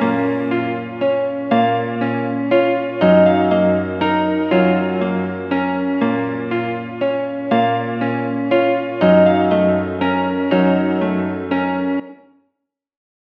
AV_BlxckEyes_120bpm_Bbmin
AV_BlxckEyes_120bpm_Bbmin.wav